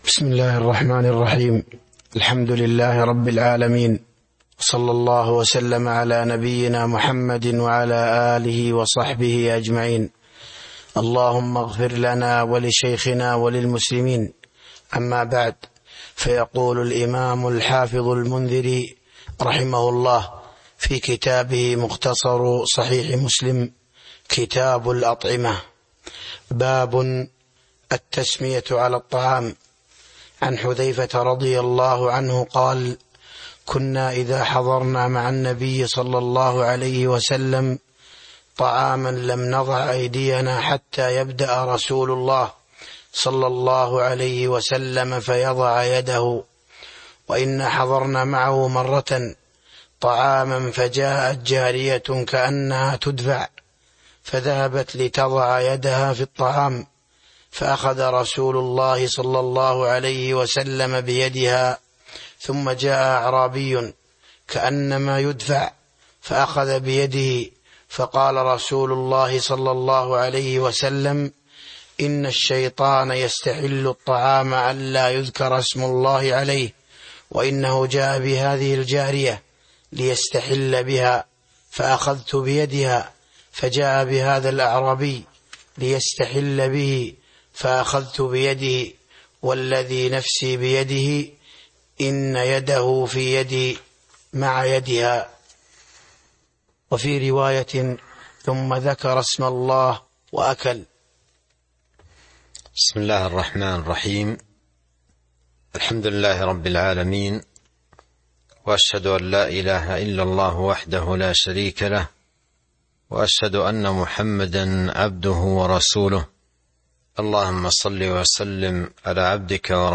تاريخ النشر ٢٢ جمادى الآخرة ١٤٤٣ هـ المكان: المسجد النبوي الشيخ: فضيلة الشيخ عبد الرزاق بن عبد المحسن البدر فضيلة الشيخ عبد الرزاق بن عبد المحسن البدر باب التسمية على الطعام (01)